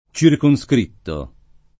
circoscritto [ © irko S kr & tto ]